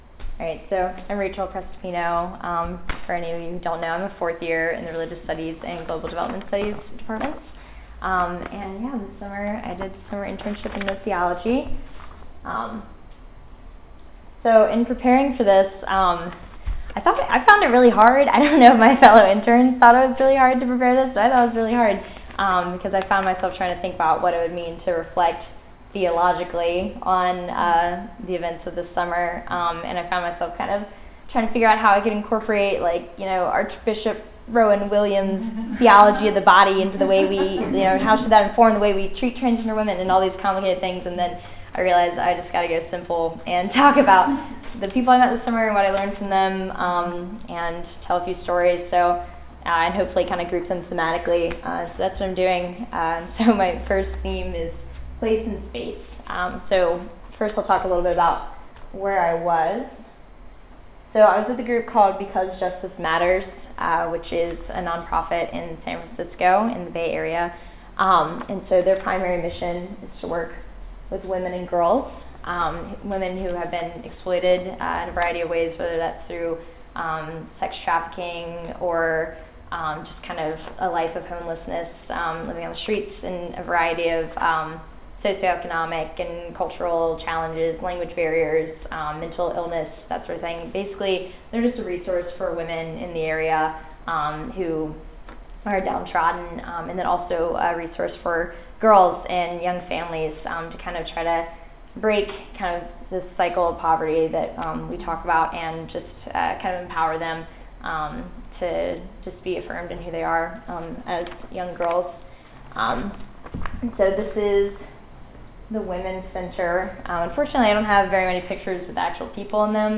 Audio Information Date Recorded: October 2015 Location Recorded: Charlottesville, VA Audio File: Download File » This audio is published by the Project on Lived Theology (PLT).